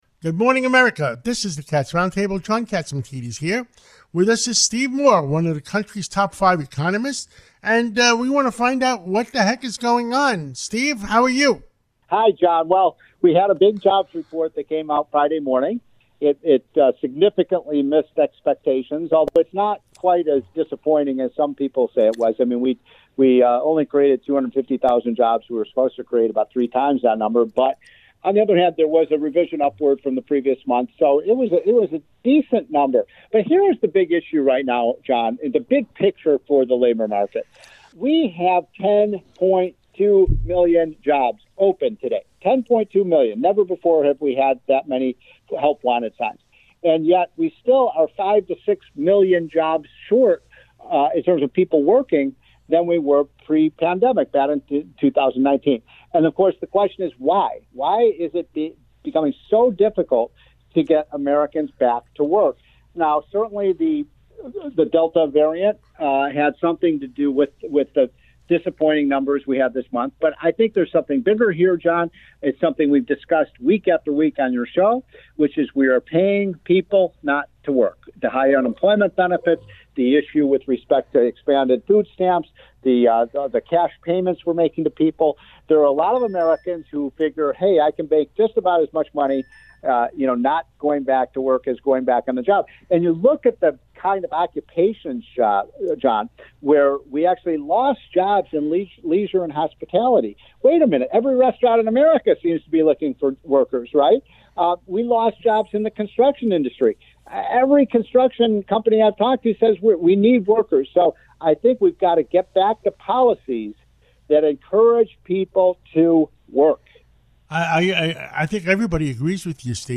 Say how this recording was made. The Cats Roundtable" on WABC 770 AM-N.Y. "It's going on for 18 months now."